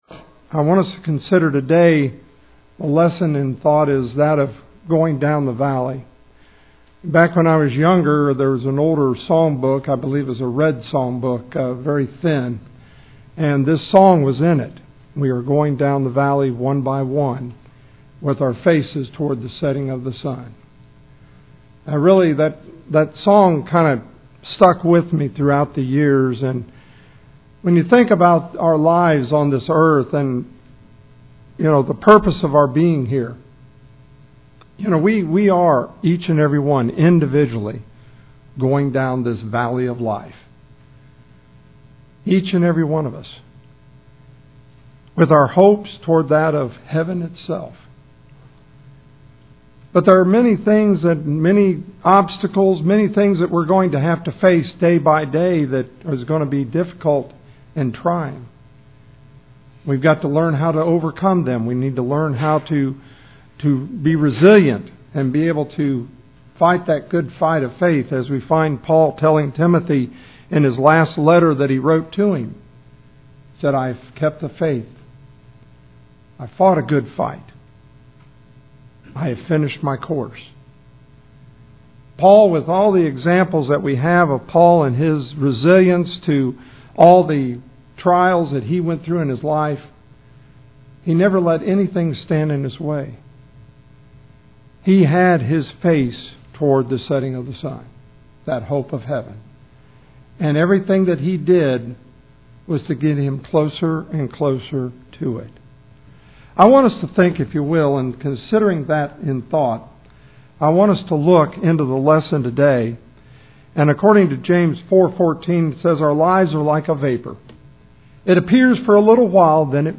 We Are Going Down the Valley – Waynesville Church of Christ